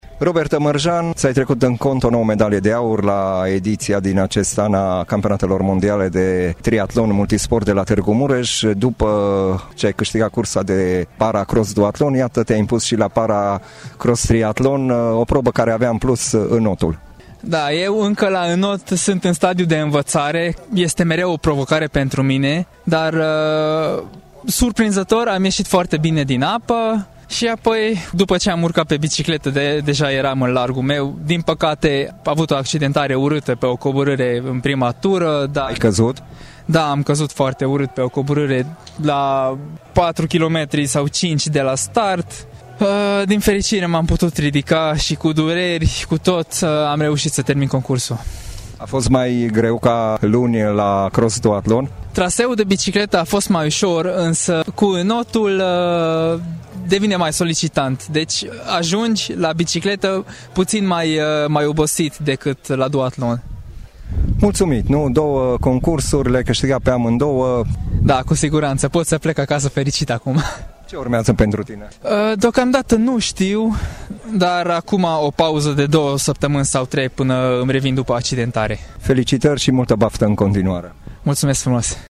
Interviu audio